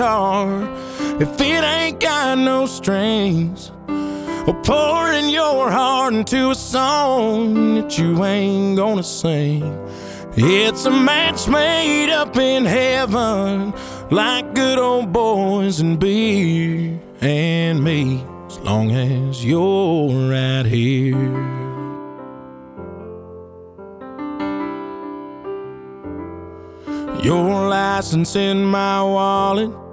• Country
ballad